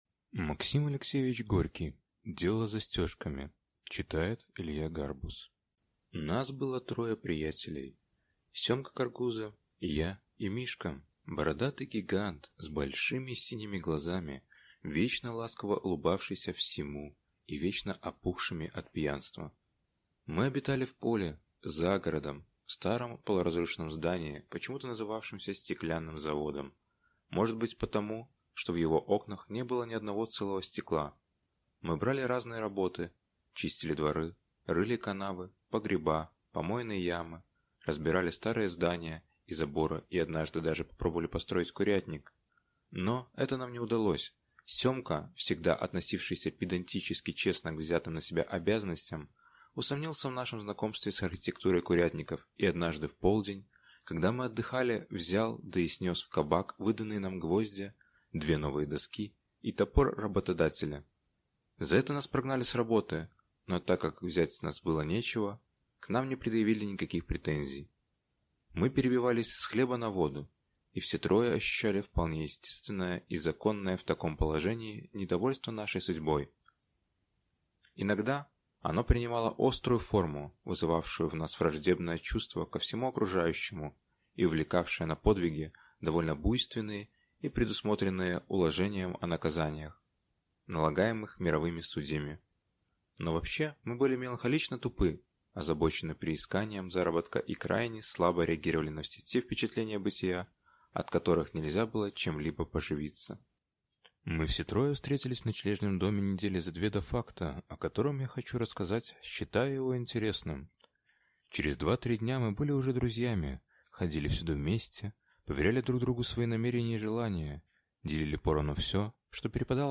Аудиокнига Дело с застёжками | Библиотека аудиокниг